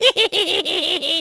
goblin.ogg